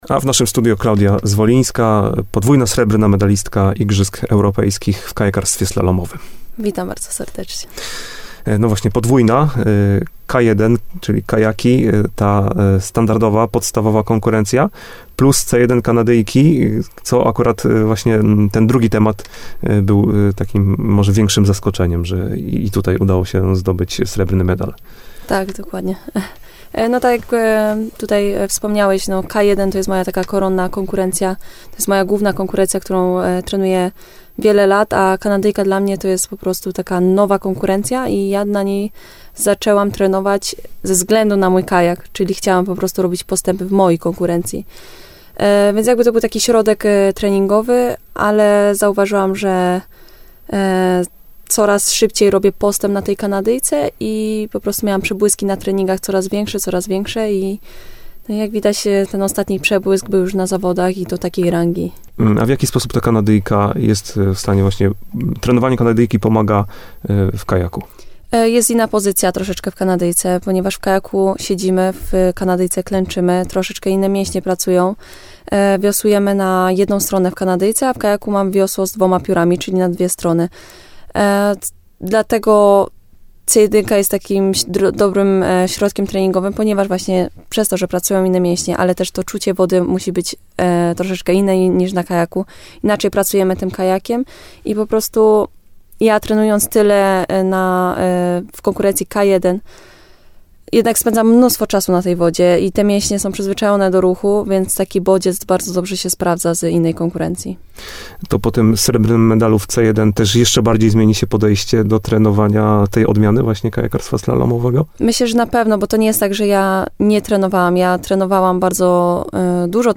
– To długo wyczekiwane medale – podkreśliła w rozmowie z radiem RDN Nowy Sącz.